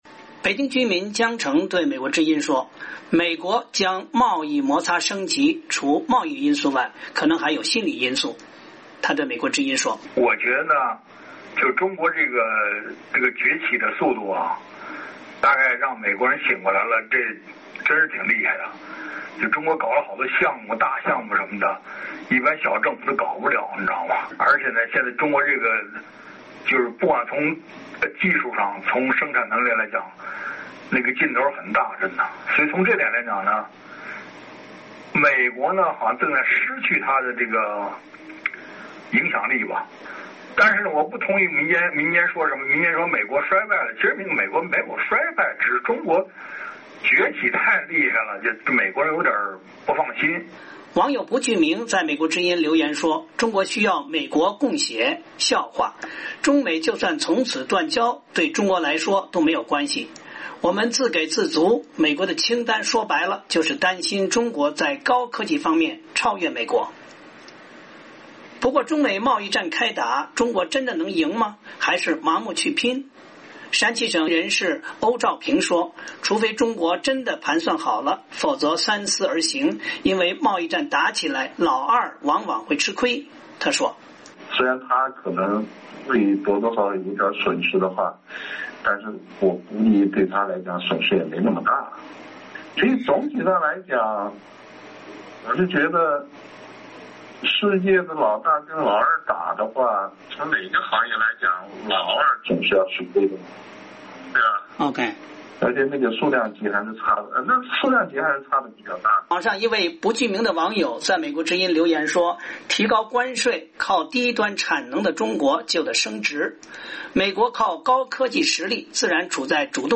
美国之音记者采访了一些普通民众，他们的文化程度各异，但都不是所谓学者和专家，他们自己也说，只是随便聊聊，错了无妨。